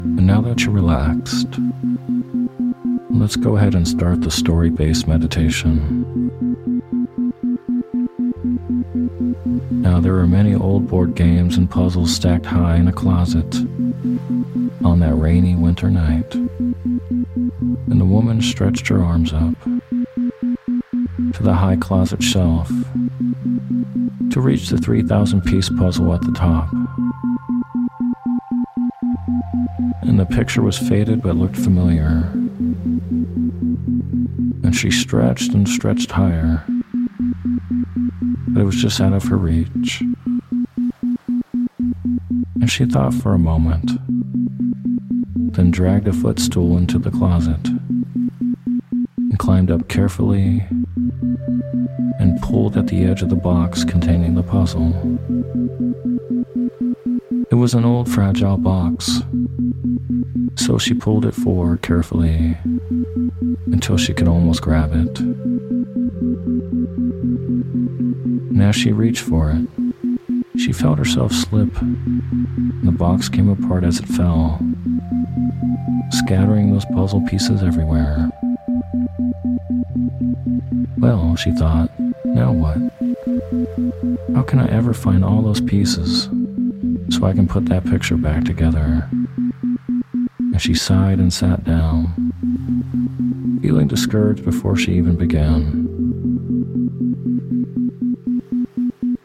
Story Based Meditation "The Puzzle" With Isochronic Tones